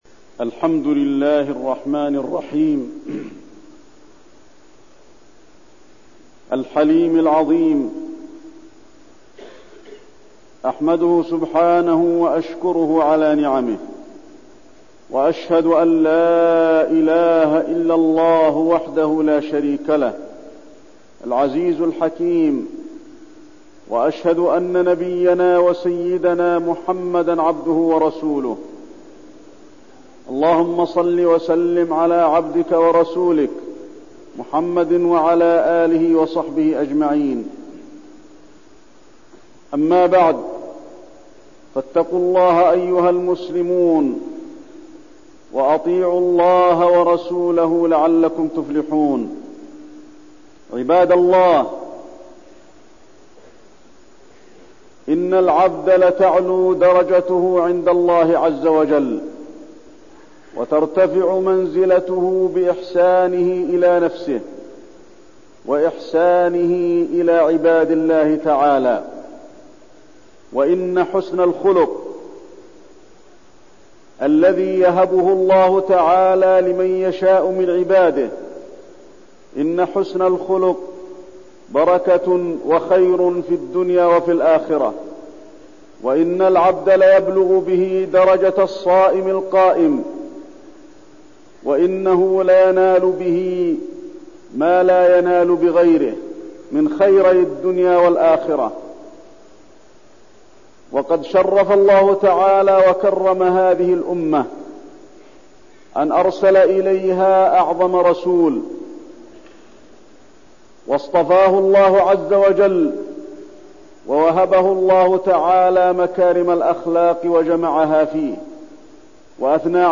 تاريخ النشر ١٩ ربيع الثاني ١٤١٠ هـ المكان: المسجد النبوي الشيخ: فضيلة الشيخ د. علي بن عبدالرحمن الحذيفي فضيلة الشيخ د. علي بن عبدالرحمن الحذيفي حسن الخلق